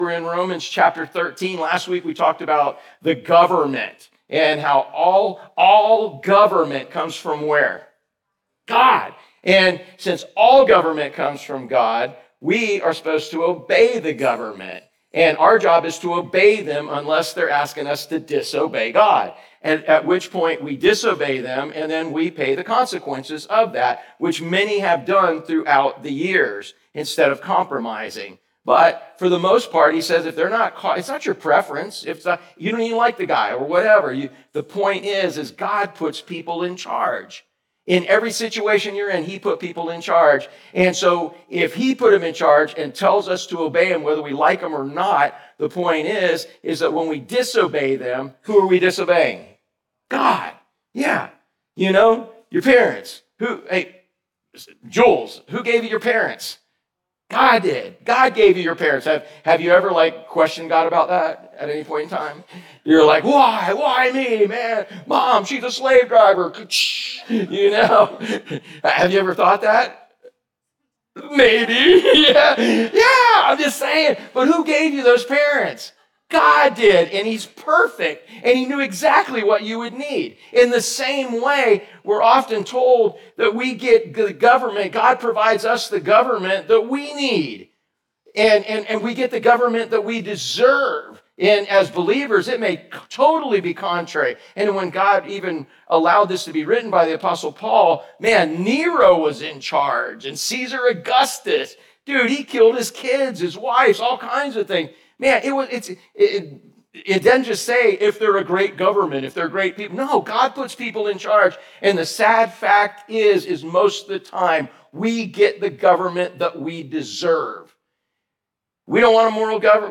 Sermons | Driftwood Church at the Beach